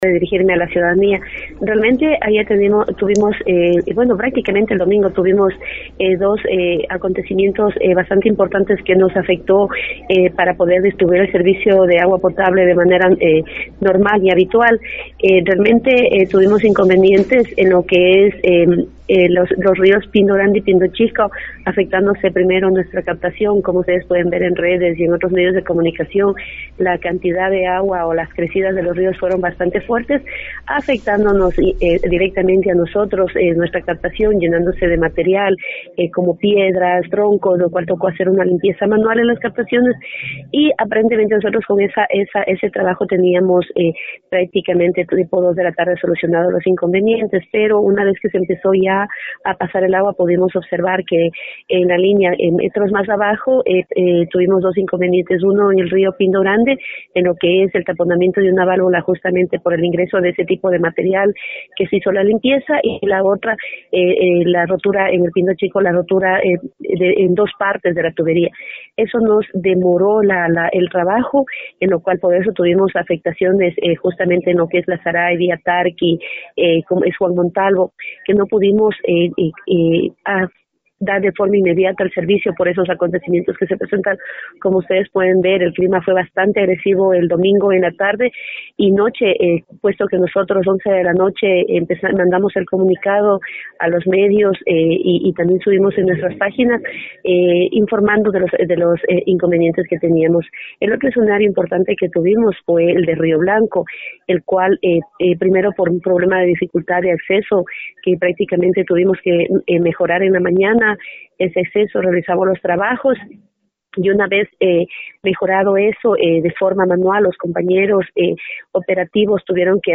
En una entrevista telefónica a través de Nina Radio de Puyo